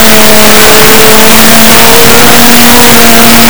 影响，录制文件的噪音相对较大。